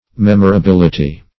Memorability \Mem`o*ra*bil"i*ty\, n.